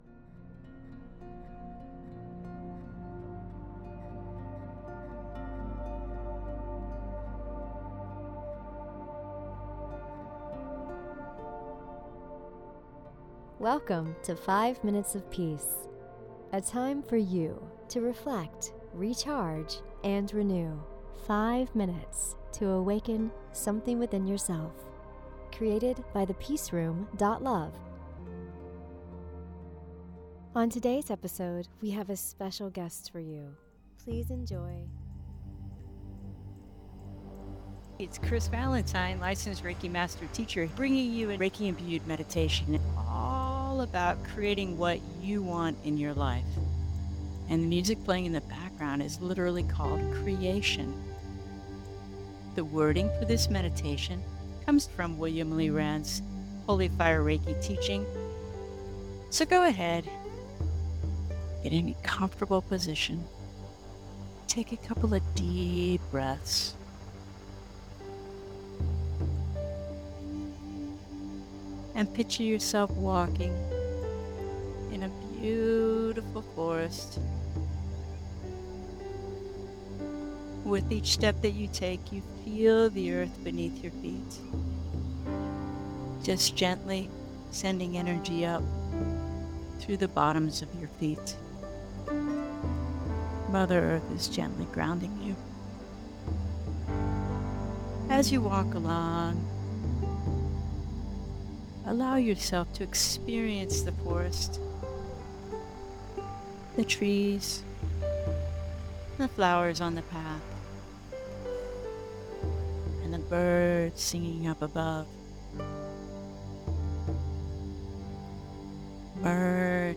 In this calming guided meditation
With soft music titled Creation playing in the background